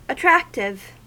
Ääntäminen
US : IPA : [ə.ˈtɹæk.tɪv]